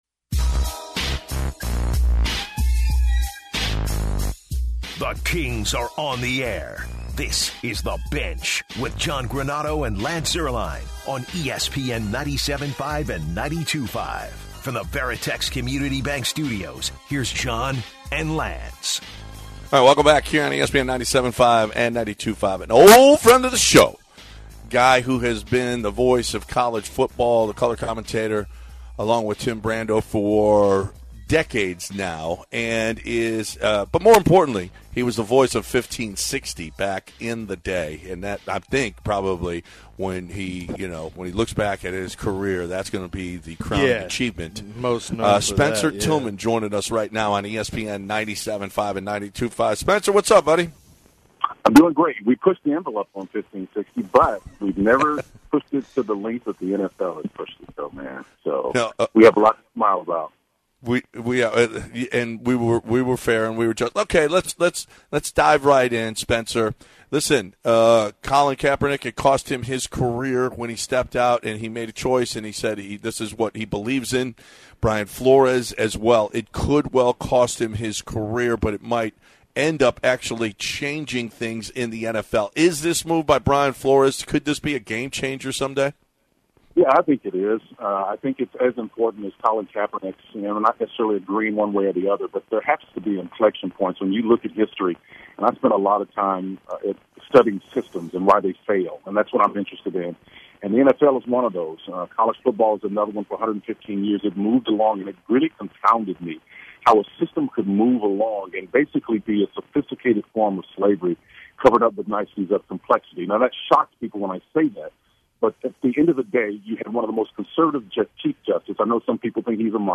calls in to discuss the Brian Flores lawsuit against the NFL and NIL in college sports.